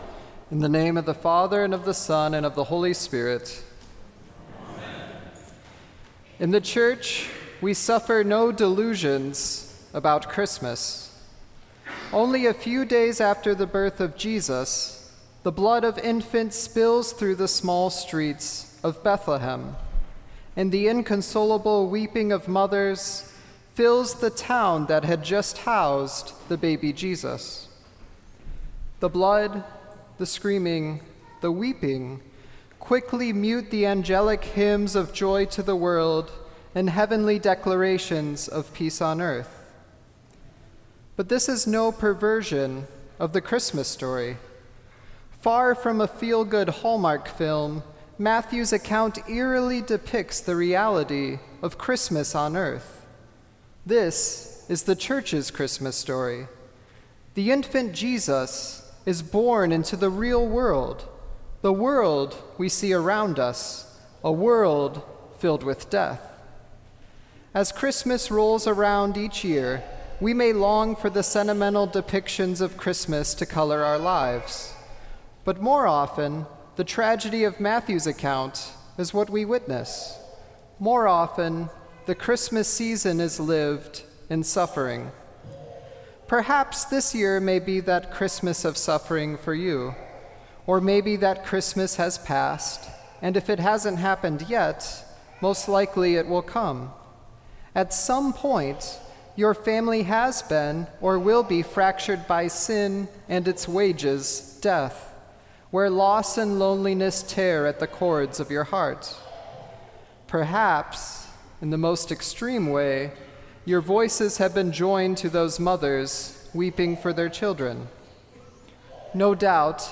Sermon for The Holy Innocents, Martyrs